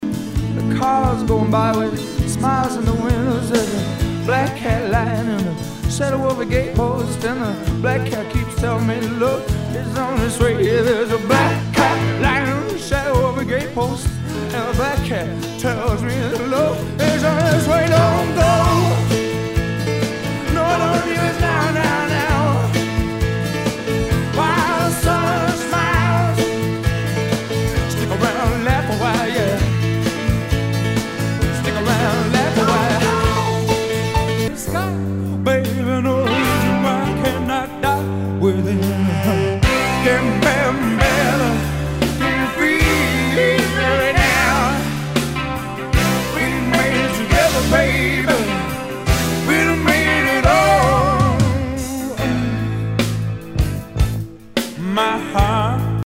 ROCK/POPS/INDIE
ナイス！.ポップ・ロック！